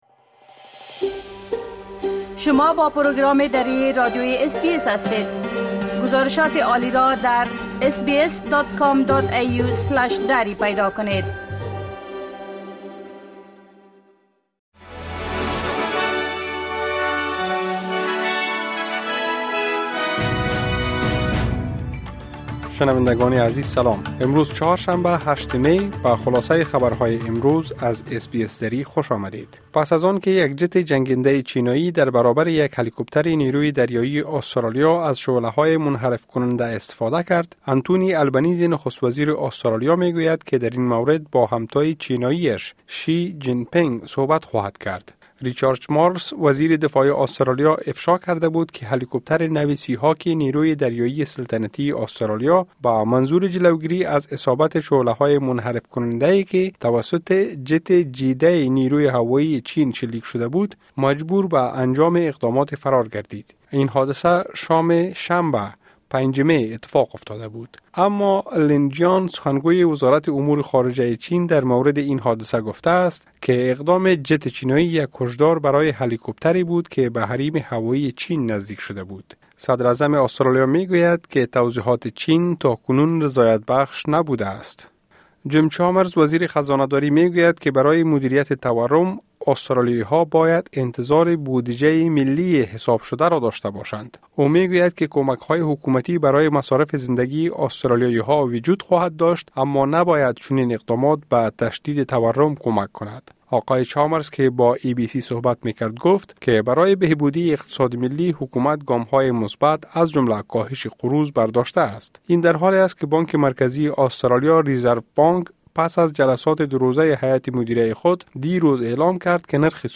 خلاصۀ مهمترين اخبار روز از بخش درى راديوى اس بى اس|۸ می ۲۰۲۴